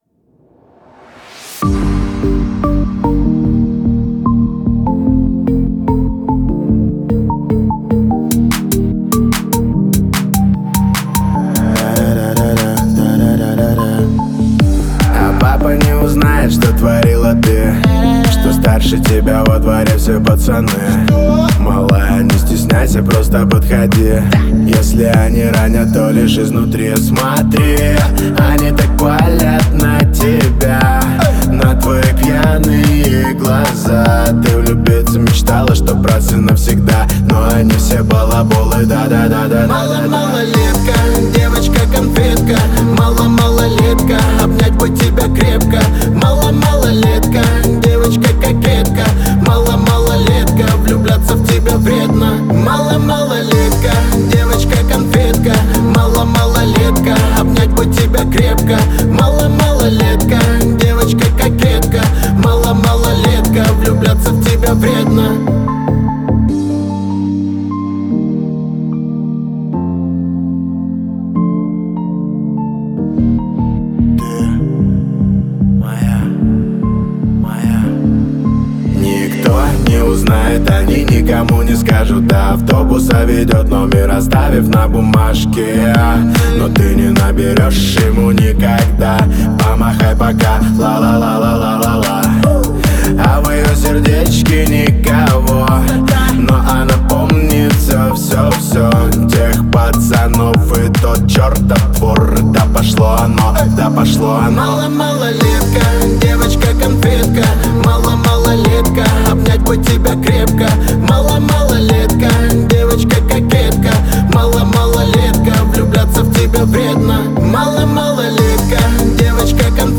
запоминающимся битом и мелодичными припевами